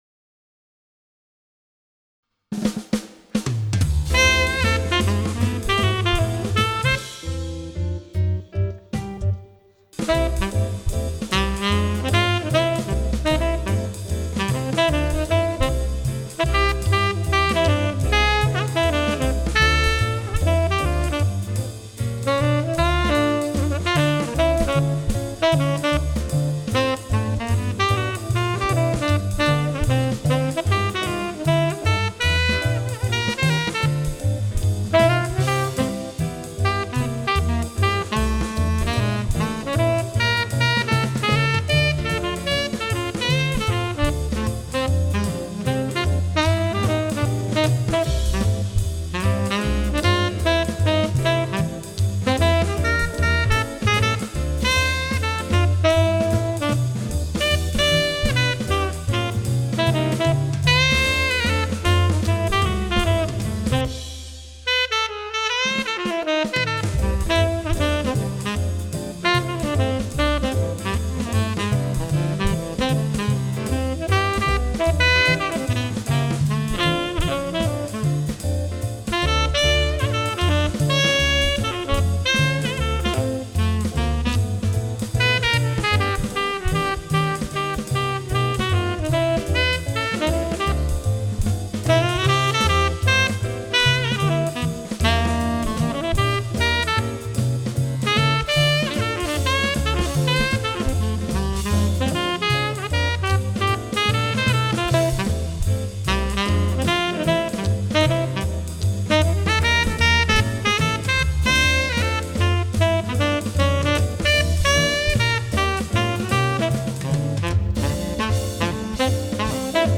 12 Jazz Etudes Based on Popular Standards